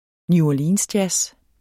Udtale [ njuɒˈliːnsˌdjas ]